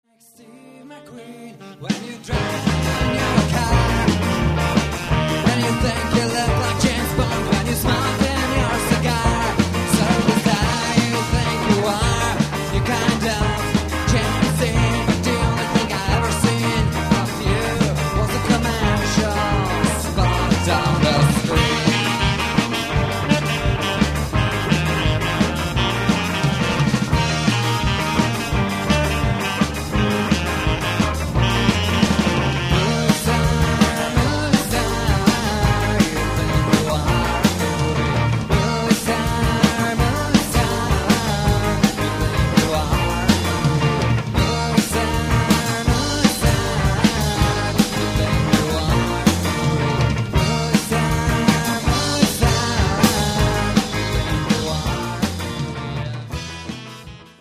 Nahráno živě na koncertu v klubu Prosek dne 1.3.2001